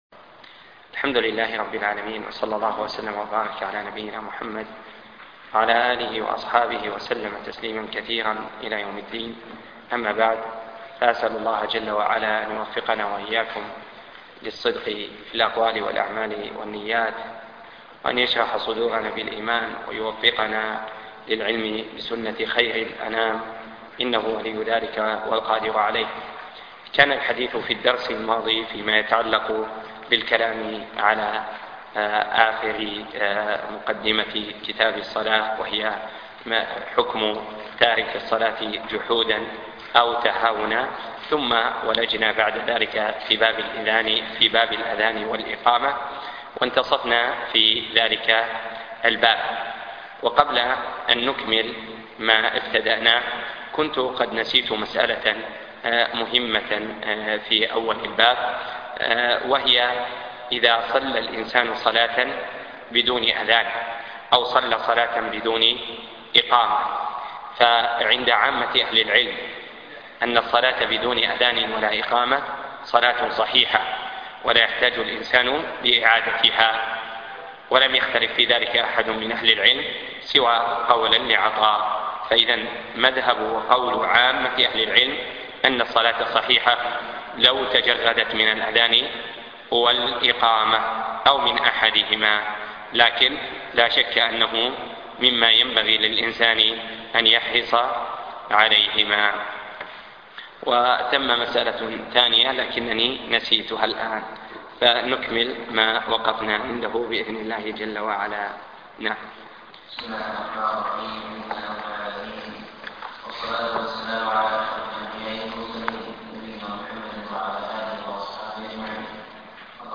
زاد المستقنع - باب إزالة النجاسة - باب الحيض - الدرس (15)